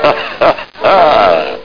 hahaha.mp3